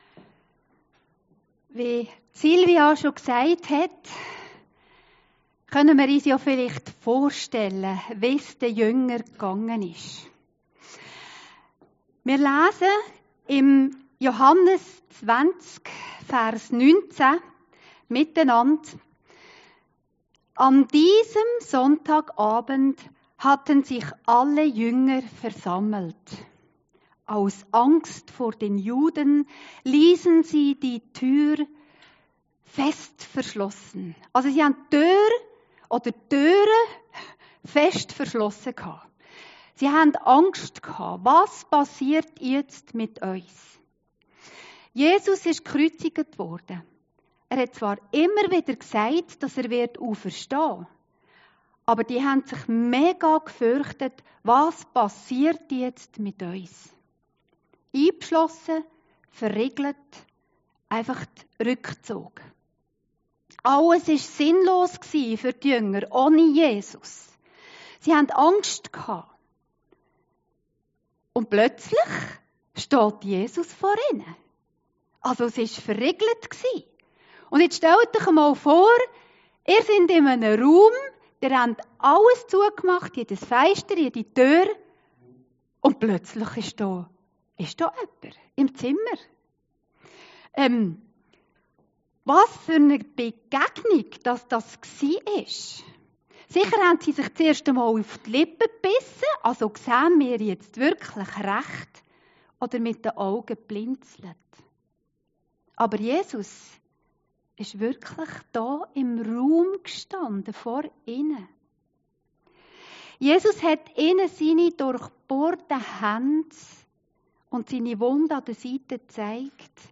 Predigten Heilsarmee Aargau Süd – DER ZWEIFELNDE THOMAS